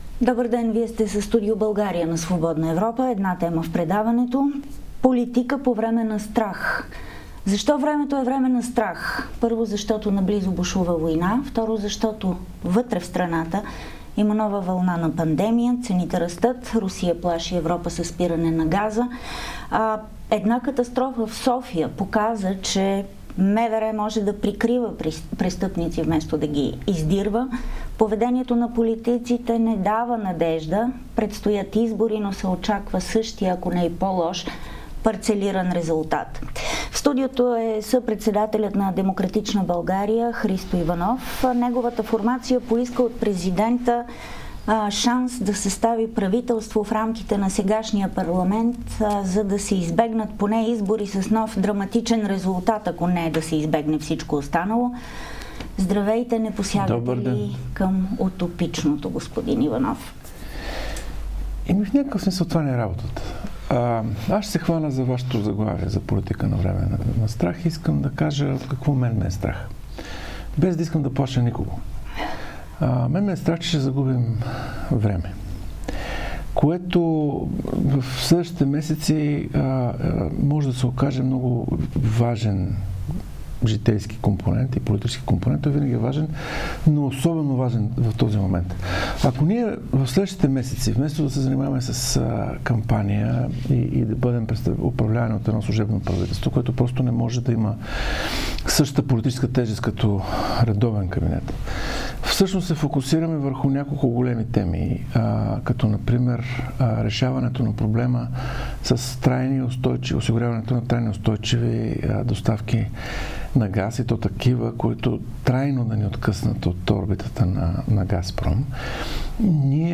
Ценностните различия между партиите в парламента в момента са много силни, но след избори разломът може да стане още по-голям. Какво още каза съпредседателят на "Демократична България" пред Свободна Европа, слушайте в звуковия файл.